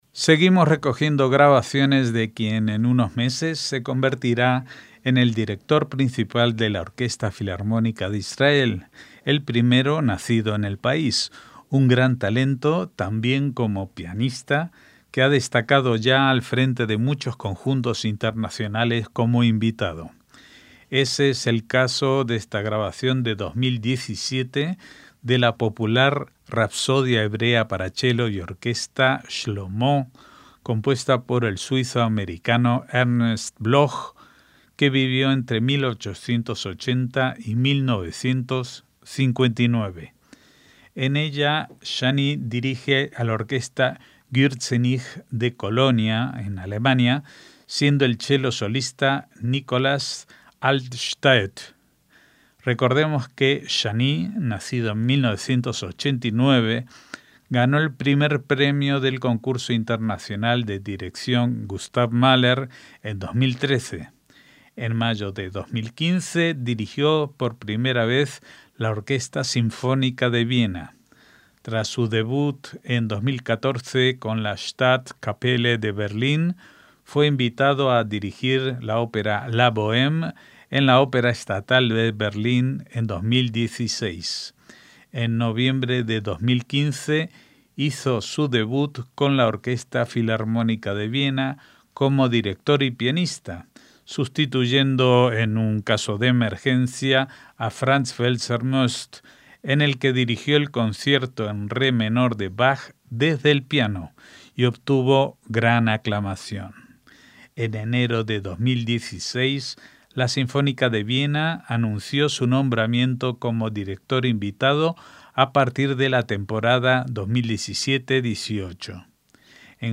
MÚSICA CLÁSICA
rapsodia hebrea para chelo y orquesta